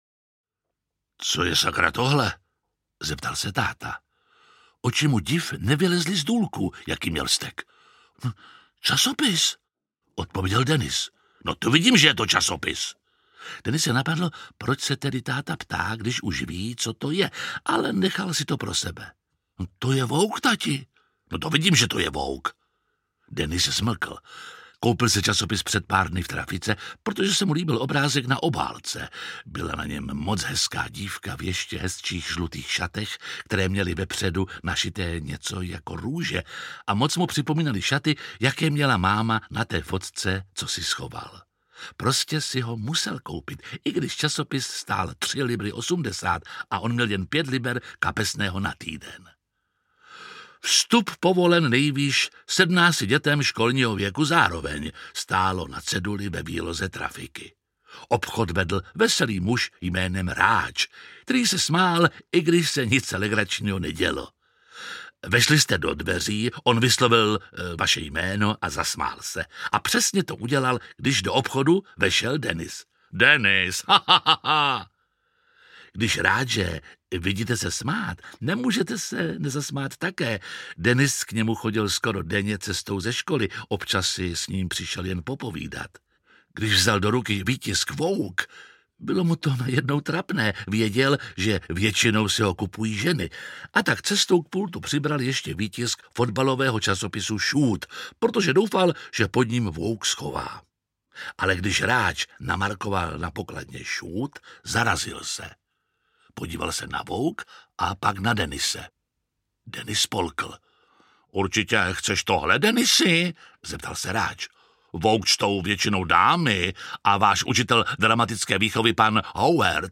Kluk v sukních audiokniha
Ukázka z knihy
• InterpretJiří Lábus